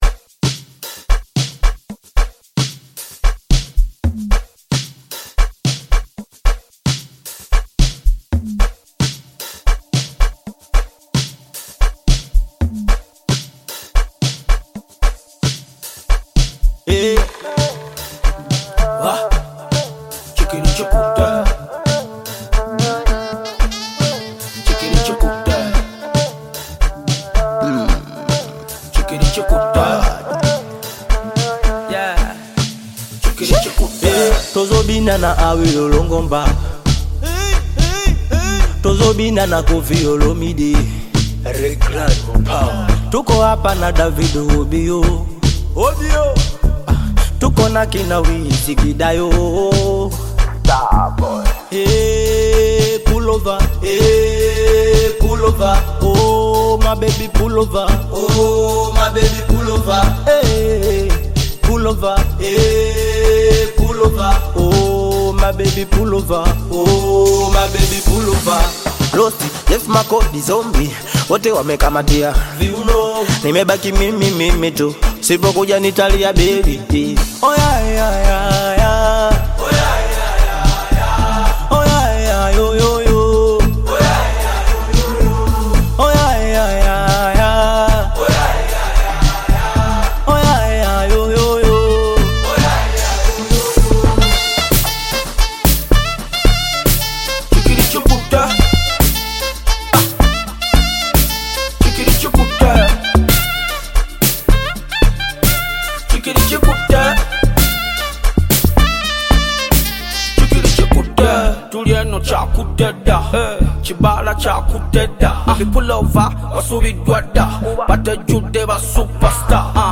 Bongo Flava
Bongo Flava You may also like